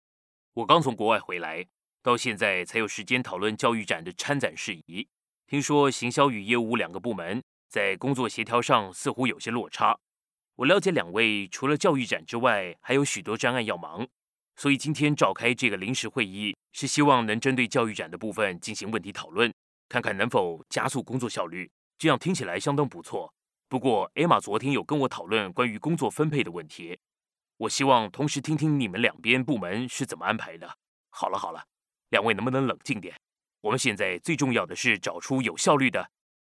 Traditional (Tayvan) Seslendirme
Erkek Ses